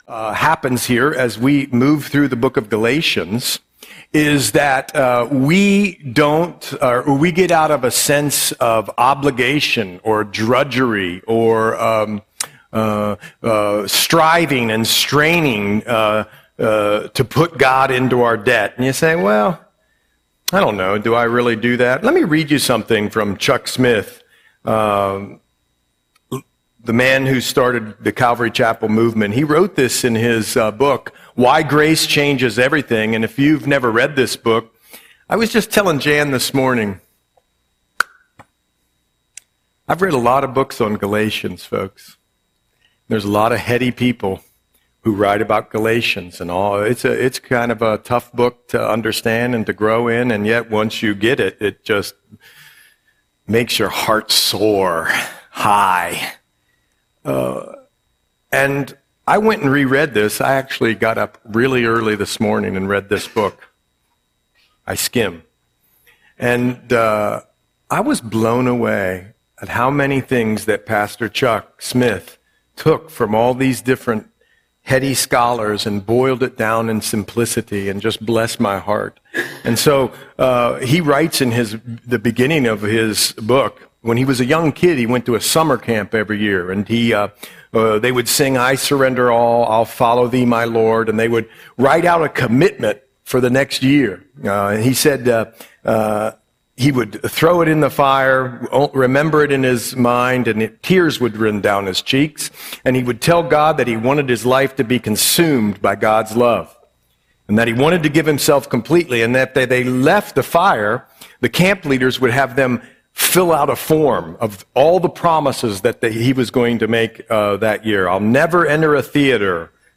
Audio Sermon - June 1, 2025